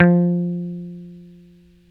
Index of /90_sSampleCDs/Roland LCDP02 Guitar and Bass/BS _Rock Bass/BS _Dan-O Bass